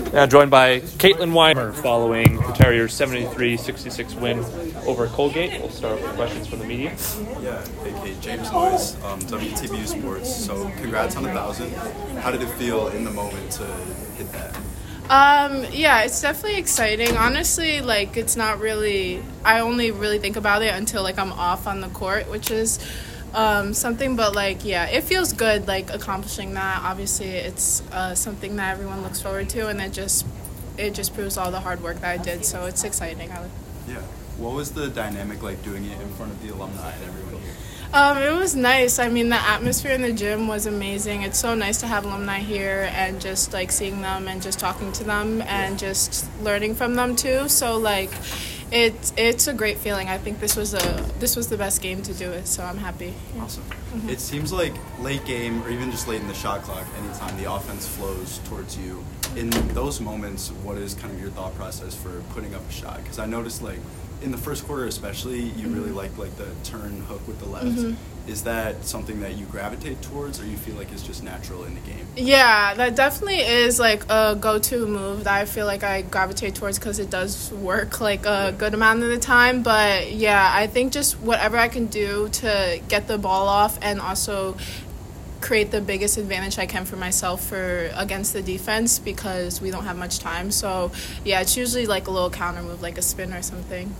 WBB_Colgate_Postgame.mp3